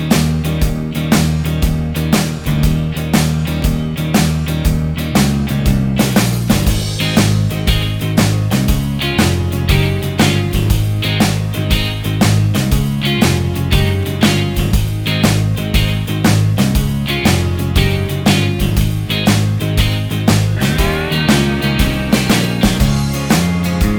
No Harmony Pop (1980s) 3:31 Buy £1.50